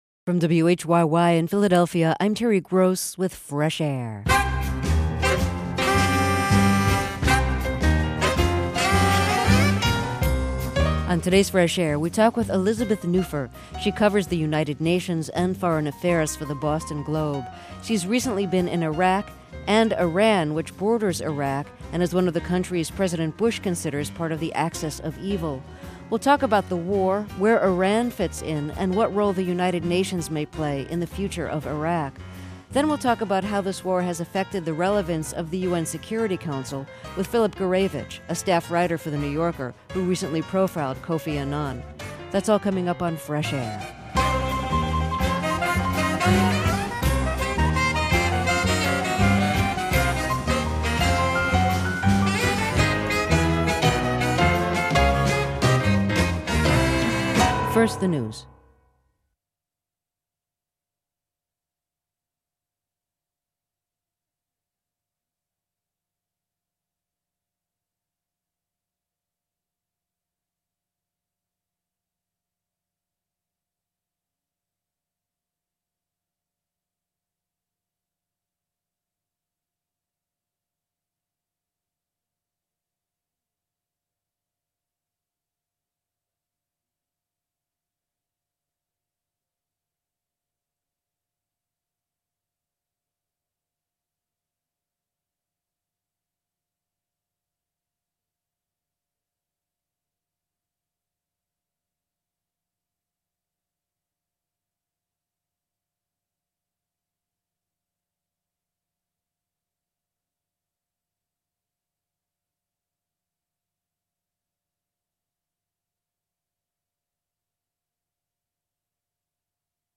Interview Jonathan Schell